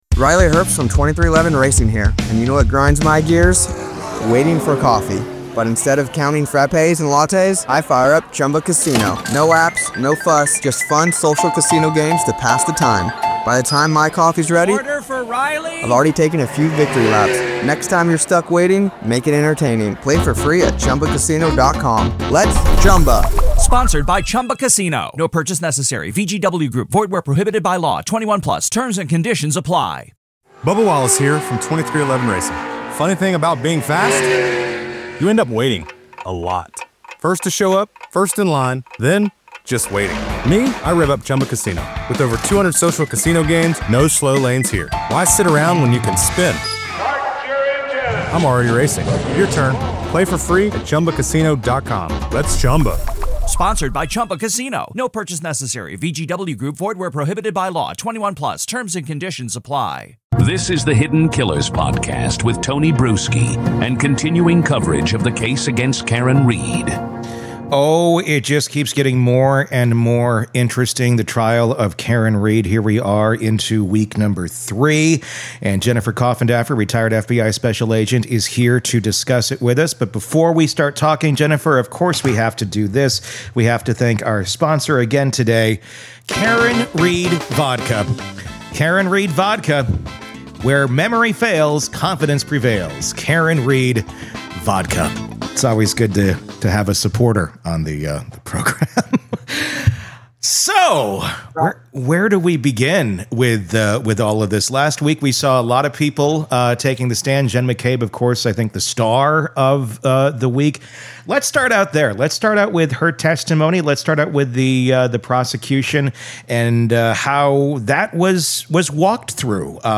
In this comprehensive analysis, we delve into the perplexing aspects of the Karen Read case, focusing on the events between April 30 and May 2.